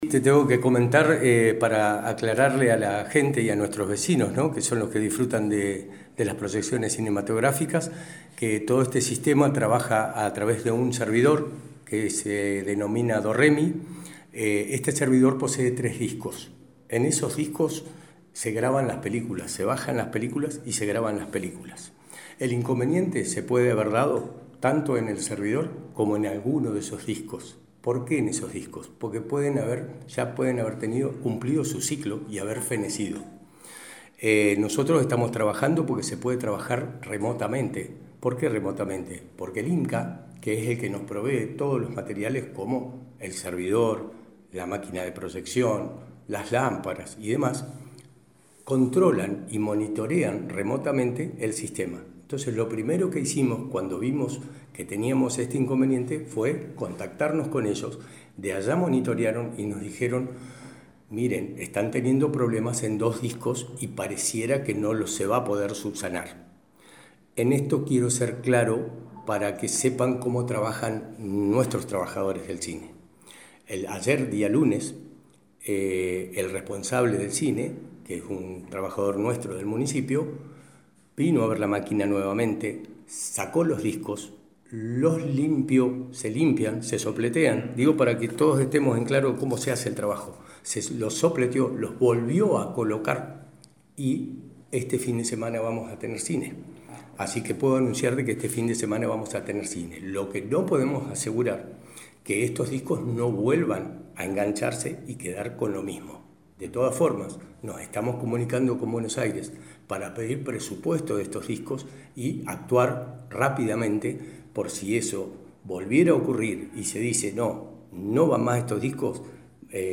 El fin de semana pasado el Cine debió suspender sus proyecciones a raíz de un desperfecto que se detecto en el equipo informático que posee la sala, explicó el Subsecretario de Cultura Leopoldo Zanelli. Habiendo realizado una limpieza de manera local desde el INCAA se verifico remotamente que el equipo ha vuelto a quedar en condiciones de reanudar las funciones, lo que se hará desde este jueves.